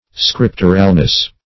Scripturalness \Scrip"tur*al*ness\, n. Quality of being scriptural.